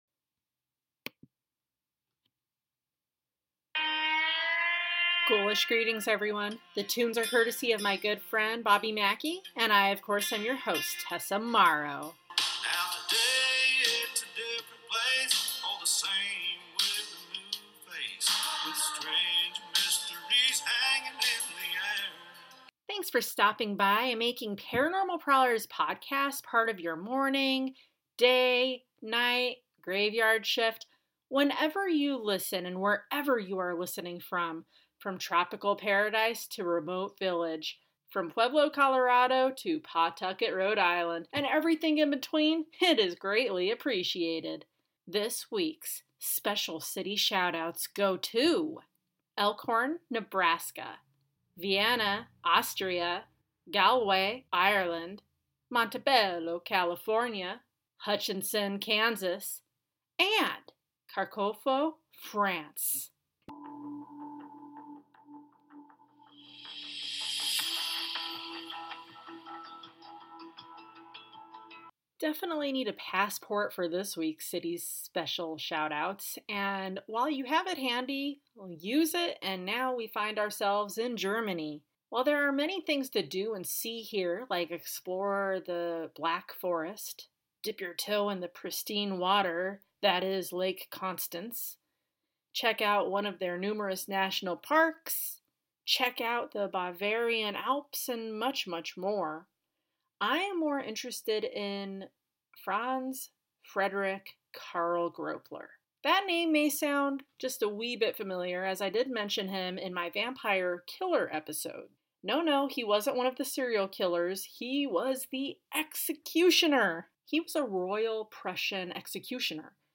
VOICE OVER